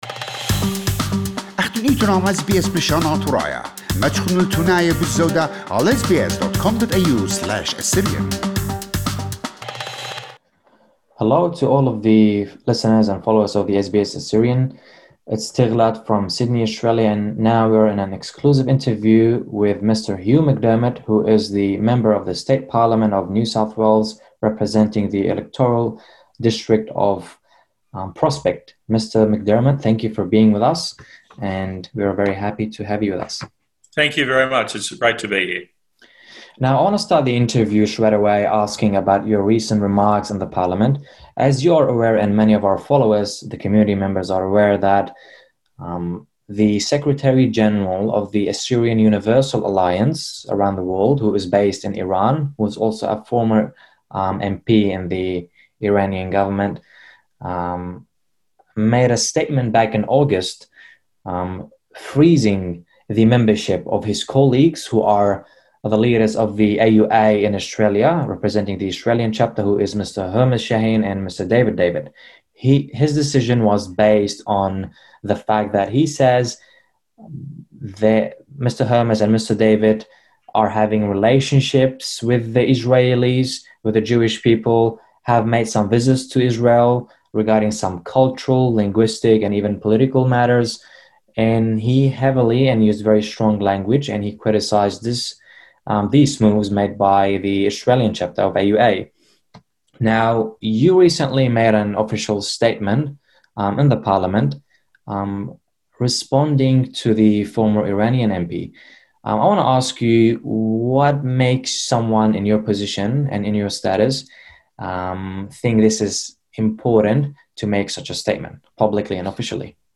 Mr. McDermott began the interview by highlighting the achievements of the Assyrian Universal Alliance Australian Chapter in the community. Our guest mentioned the recognition of the Assyrian genocide by the NSW parliament as an example of their achievements.